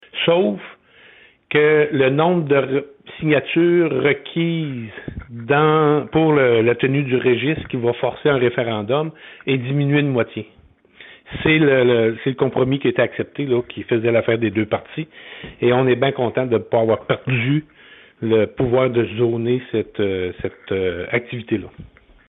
Cependant, les règles entourant cette consultation ont été modifiées comme l’explique Guy St-Pierre, maire de Manseau et membre du conseil d’administration de la FQM :